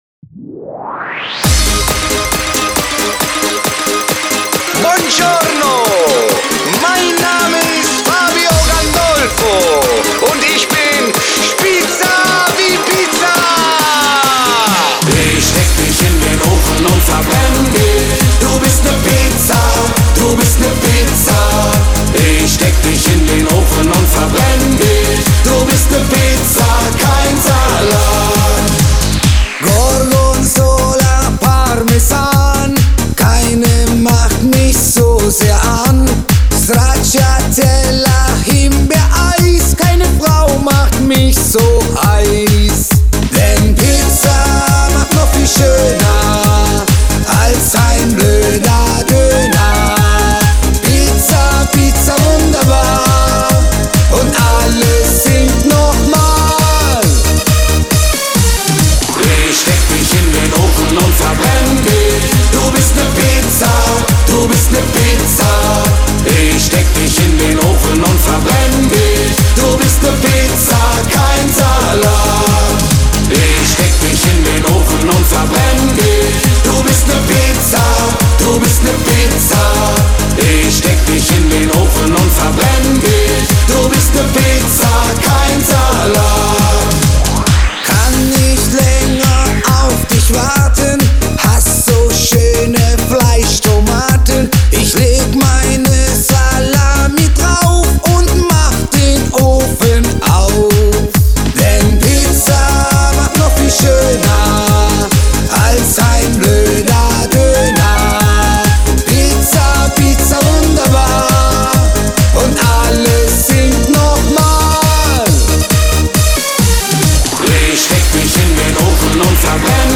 • Sänger/in